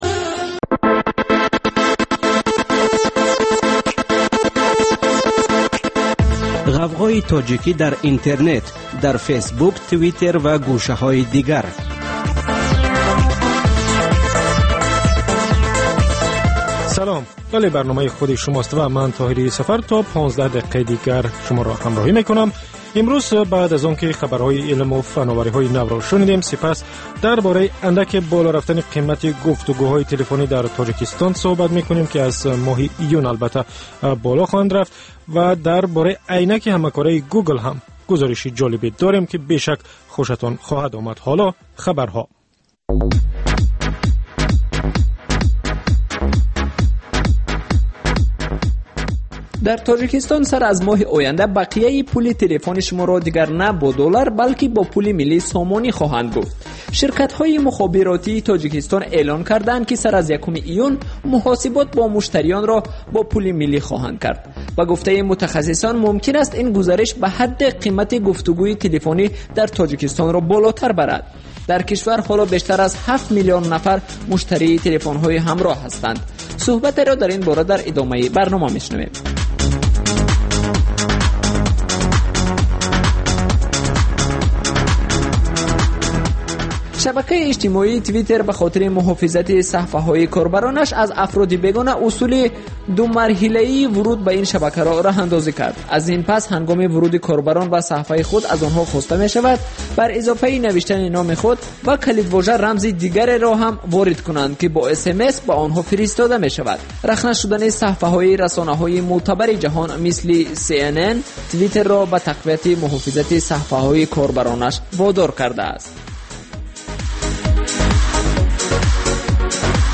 Талоши чеҳранигорӣ аз афроди хабарсоз ва падидаҳои муҳими Тоҷикистон, минтақа ва ҷаҳон. Гуфтугӯ бо коршиносон.